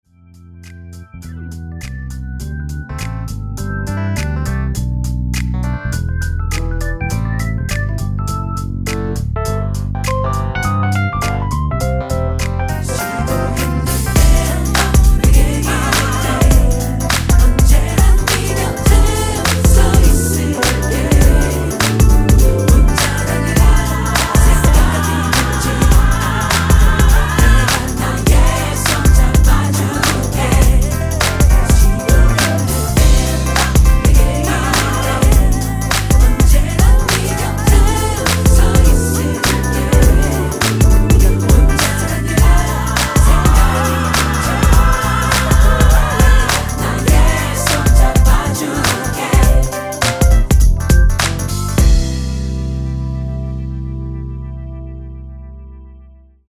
MR은 2번만 하고 노래 하기 편하게 엔딩을 만들었습니다.(본문의 가사와 미리듣기 확인)
원키 코러스 포함된 MR입니다.
앞부분30초, 뒷부분30초씩 편집해서 올려 드리고 있습니다.
중간에 음이 끈어지고 다시 나오는 이유는